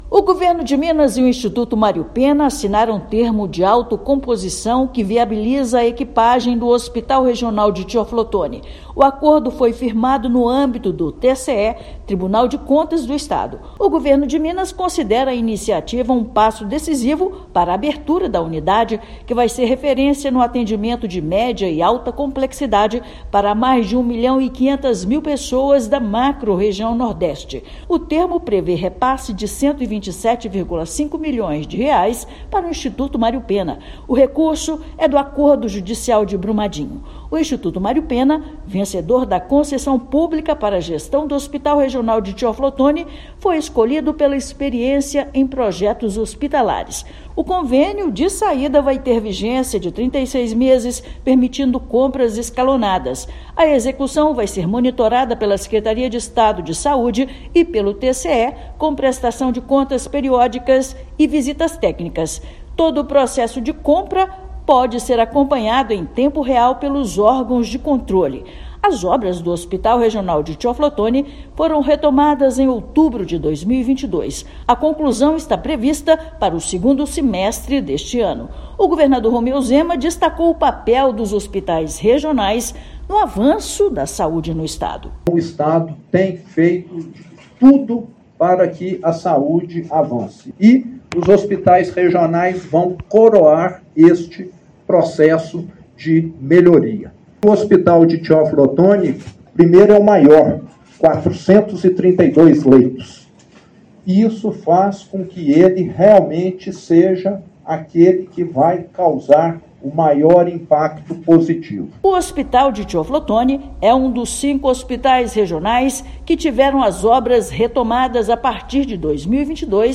Convênio de R$ 127,5 milhões garante agilidade, transparência e início da operação de 427 leitos na macrorregião de Saúde Nordeste. Ouça matéria de rádio.